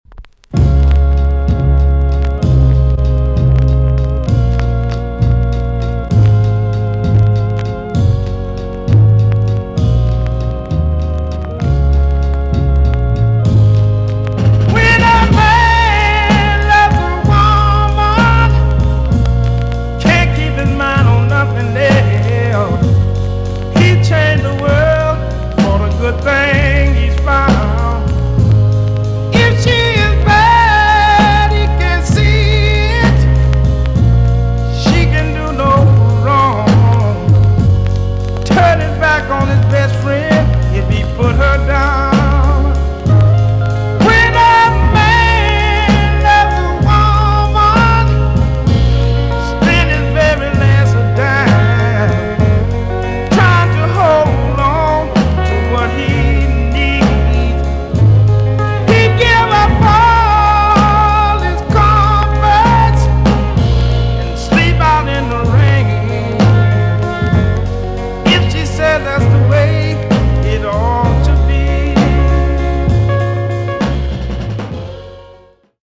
TOP >JAMAICAN SOUL & etc
EX-~VG+ 少し軽いチリノイズが入りますが良好です。